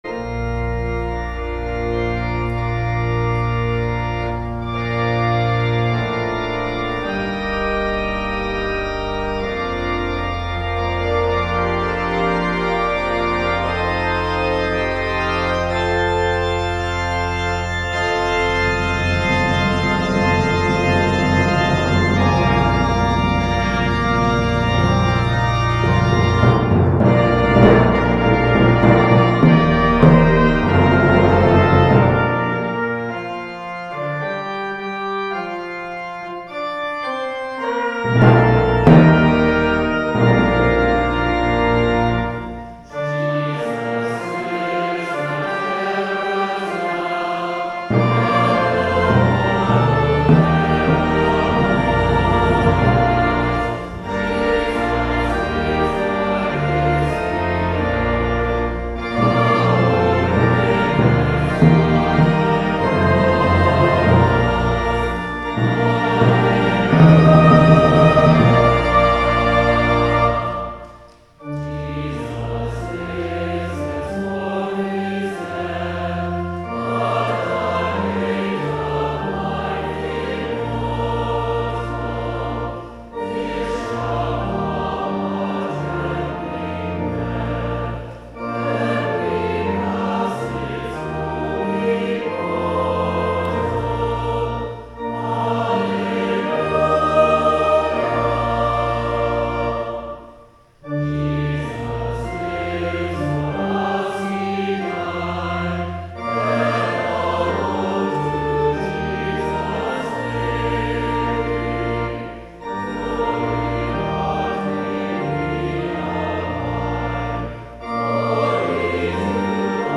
Easter Vigil